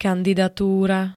kandidatúra [-d-] -ry -túr ž.
Zvukové nahrávky niektorých slov